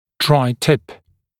[draɪ tɪp][драй тип]драй-типc (прокладка для ограничения притока слюны в ротовую полость)